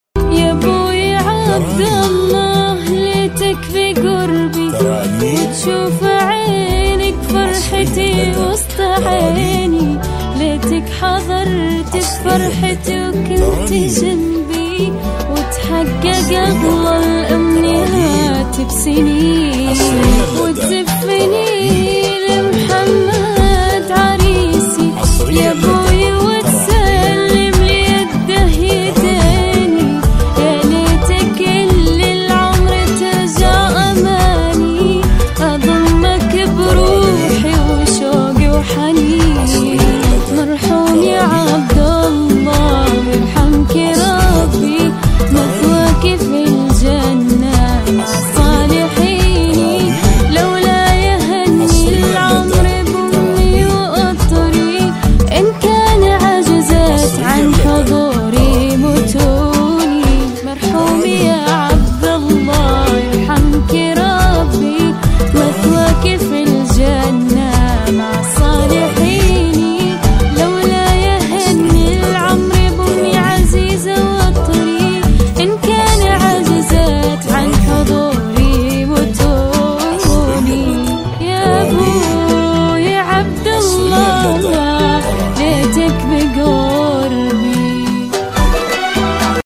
زفات